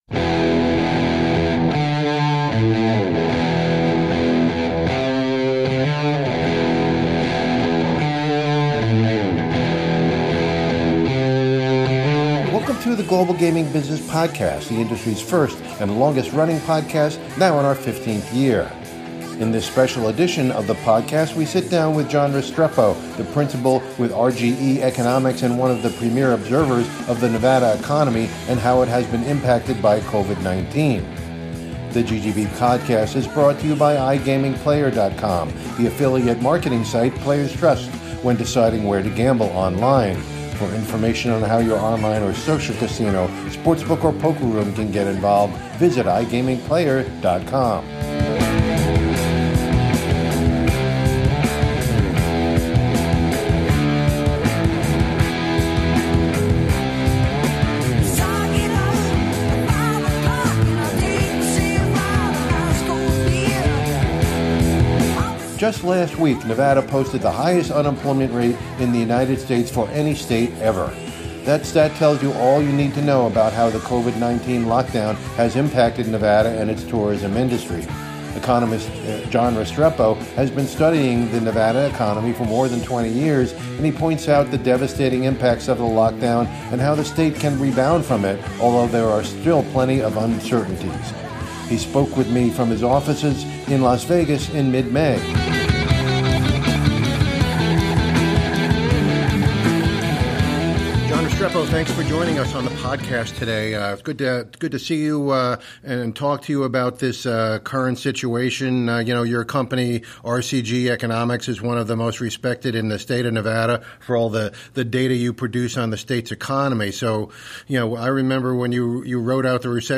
from his offices in Las Vegas in mid-May.